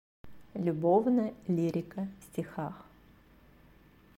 Аудиокнига Любовная лирика в стихах | Библиотека аудиокниг